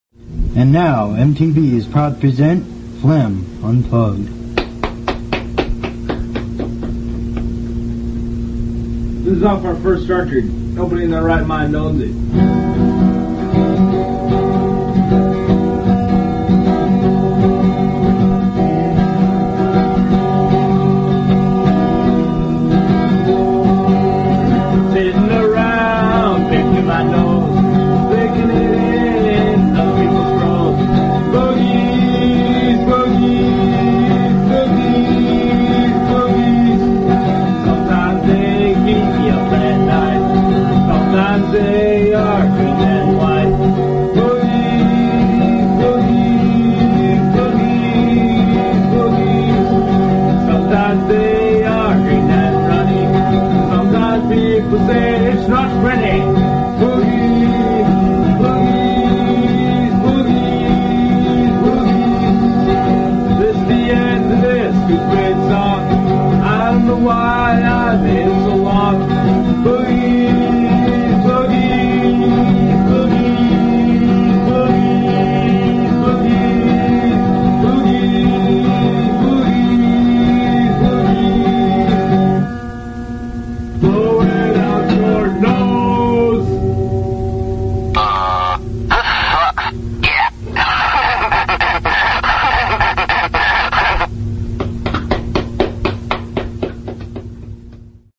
This is a song that my cousin and I made up one night just farting around. We recorded 2 versions of it, one electric and one unplugged.